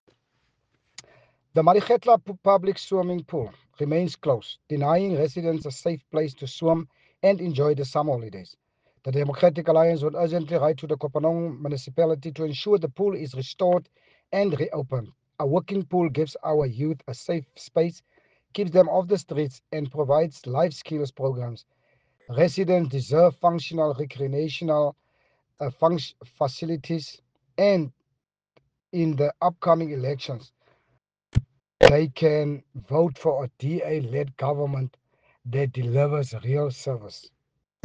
Afrikaans soundbites by Cllr Richard van Wyk and